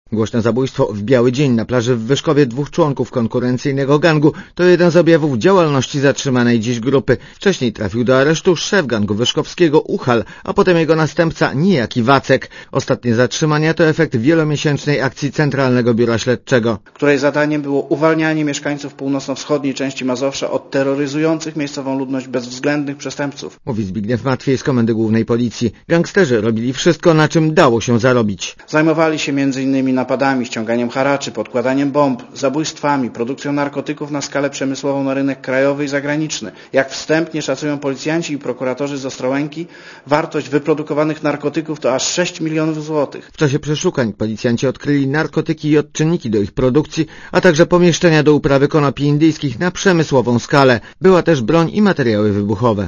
Posłuchaj relacji reportera Radia Zet (208Kb)